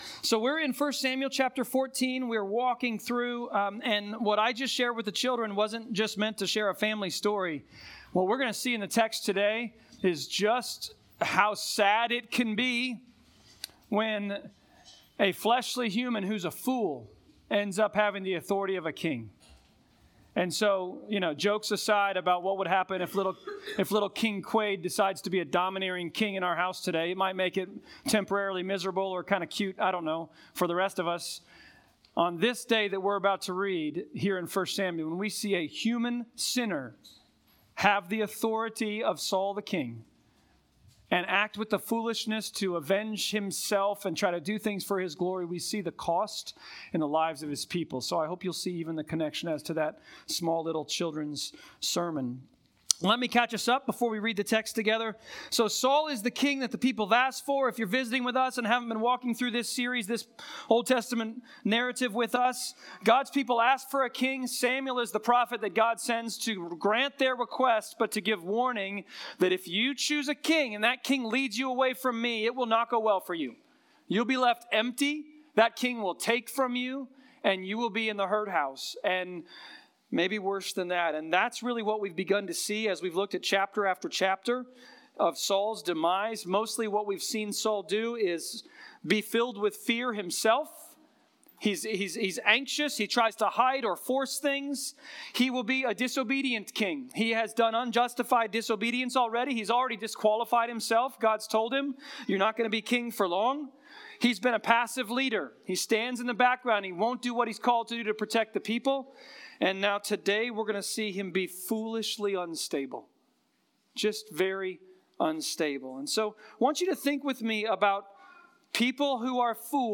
1 Samuel Passage: 1 Samuel 14.24-52 Service Type: Sermons « A Soldier of the King The Glory of the forgetLESSness and faithFULLness of God.